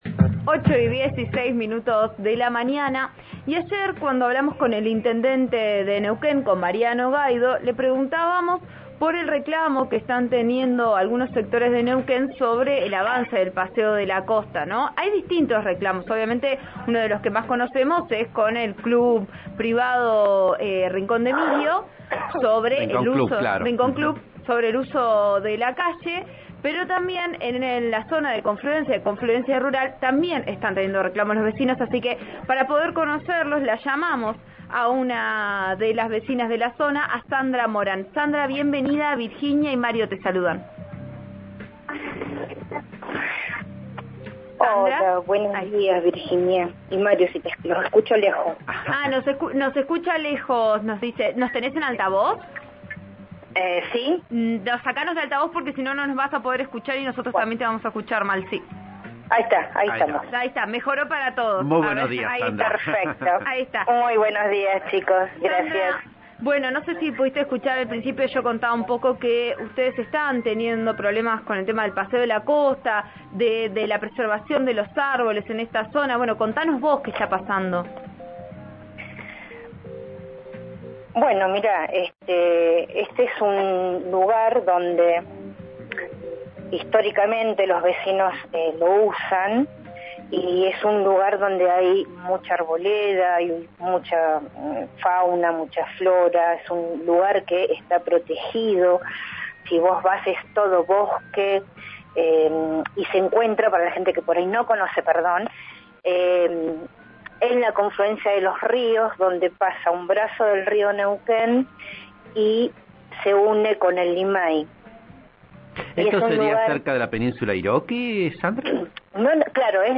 Las vecinas hablaron por RN Radio hoy e informaron que se presentaron tanto al Concejo Deliberante como a la defensoría del Pueblo para pedir intervención.